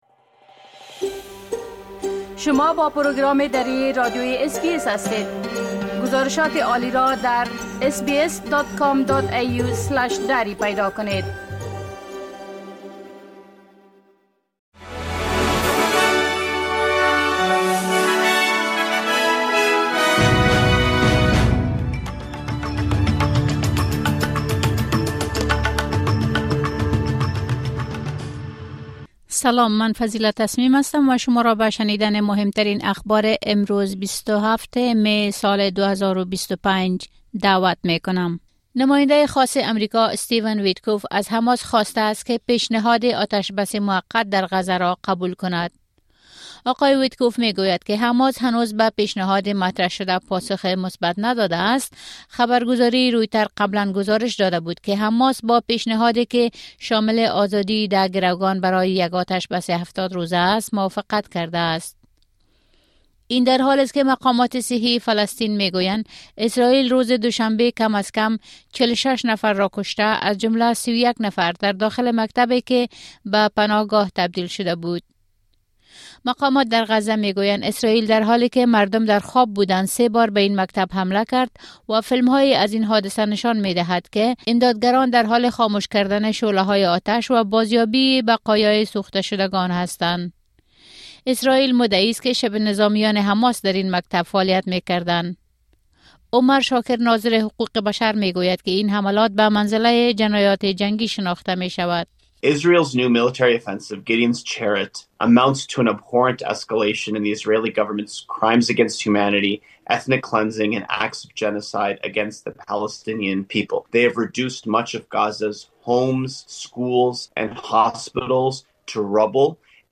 اخبار روز از بخش درى راديوى اس بى اس